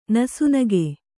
♪ nasu nage